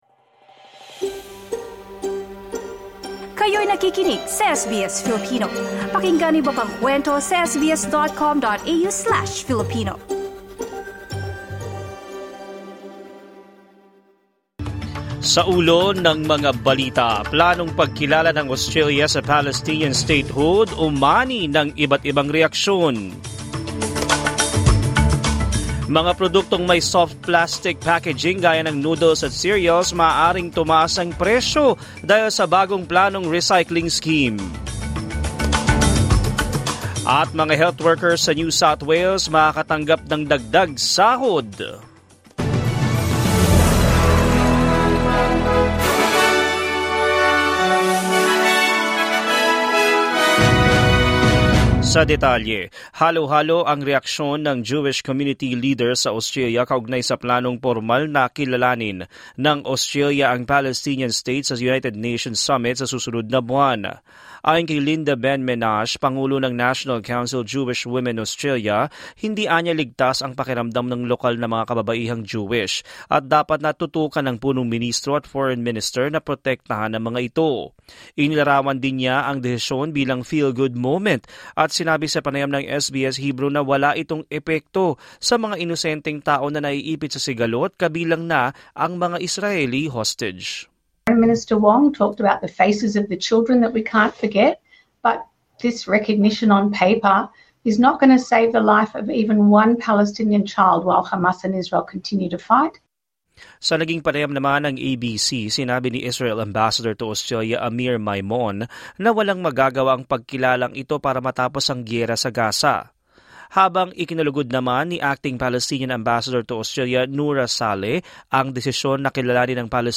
SBS News in Filipino, Tuesday 12 August 2025